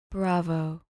Pronounced: BRAH-voh